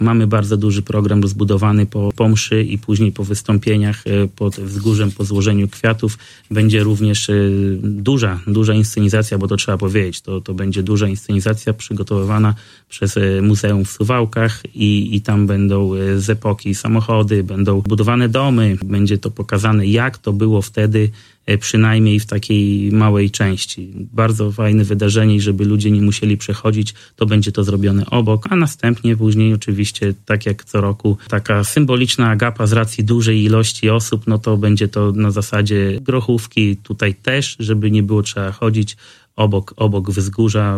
Na to odbywające się od ponad 30 lat wydarzenie zaprasza Robert Bagiński, wójt gminy Giby.